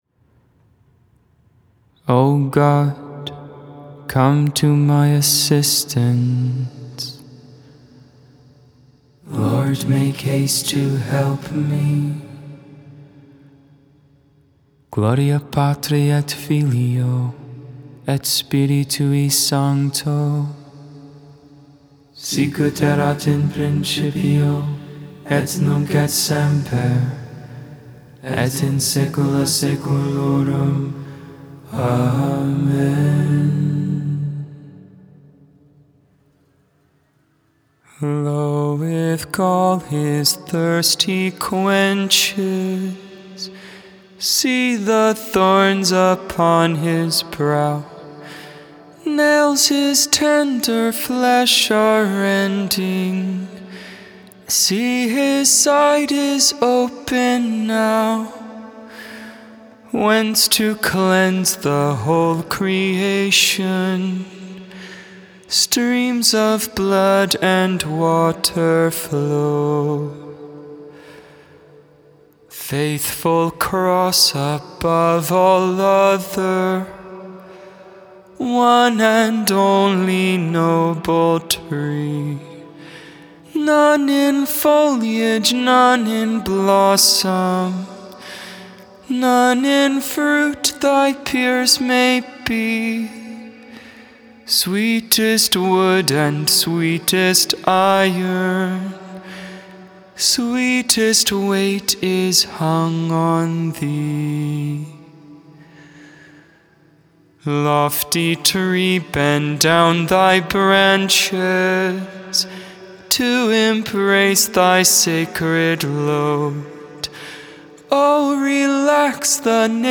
Benedictus (English, Tone 8, Luke 1v68-79) Intercessions: "Lord Have Mercy on us."